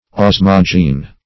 Meaning of osmogene. osmogene synonyms, pronunciation, spelling and more from Free Dictionary.
Search Result for " osmogene" : The Collaborative International Dictionary of English v.0.48: osmogene \os"mo*gene\ ([o^]s"m[-o]*j[=e]n or [o^]z"m[-o]*j[=e]n), n. [Osmose + root of Gr. ge`nos race.]